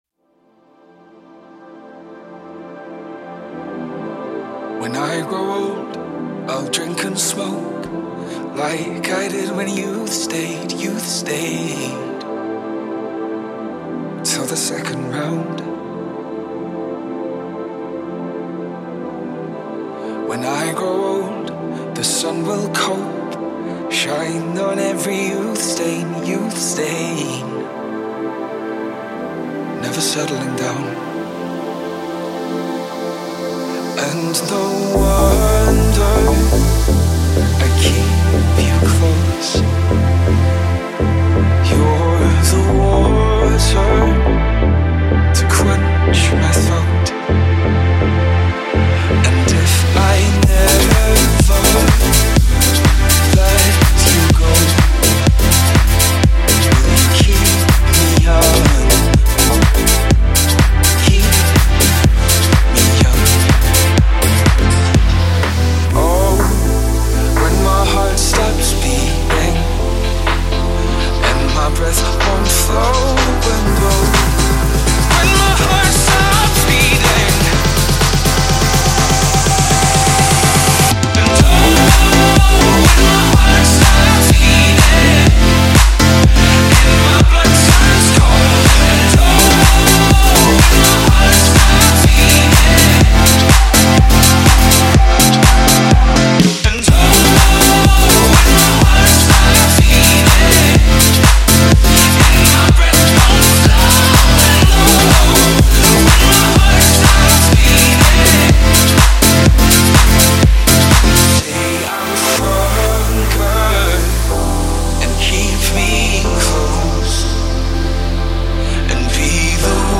· by · in EDM, Remix.
Through airy synths and an intensifying bassline Atlanta duo
bass-heavy twists and turns